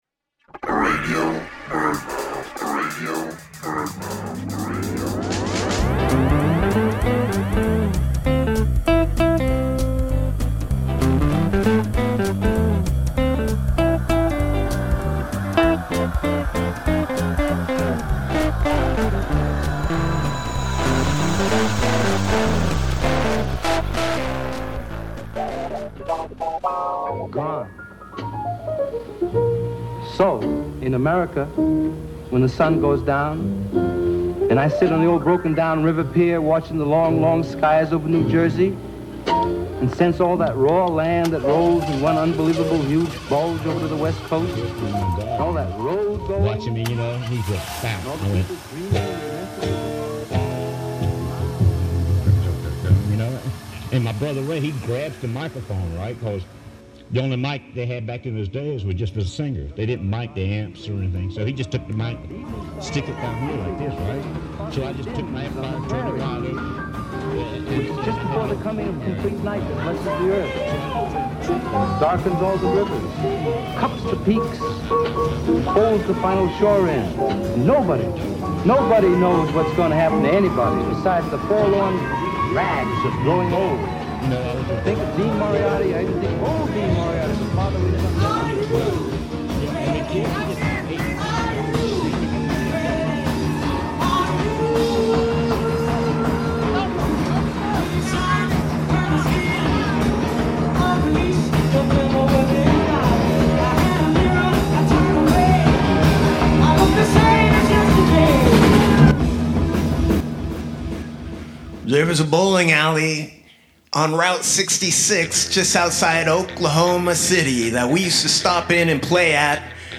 "Radio BirdMouth" is a loose-form audio road trip woven together from fragmentary spoken word narratives and sound manipulations.